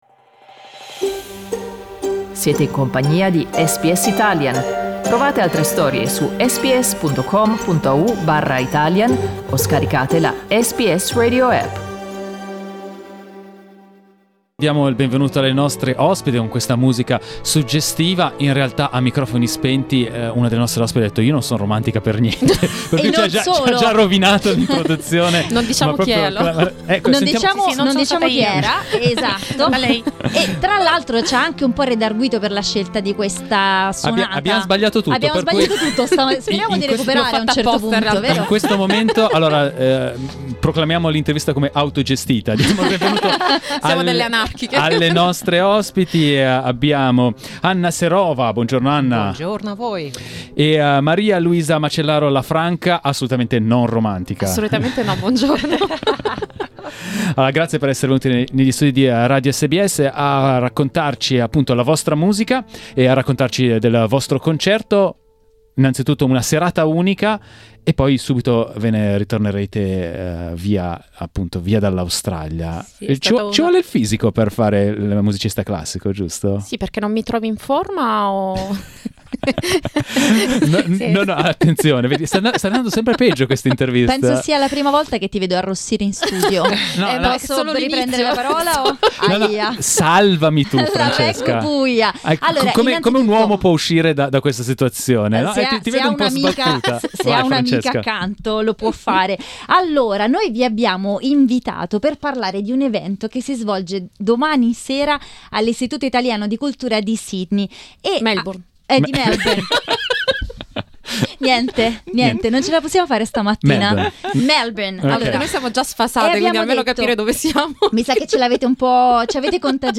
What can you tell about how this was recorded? The two musicians were our guests in the SBS studios to talk about the event.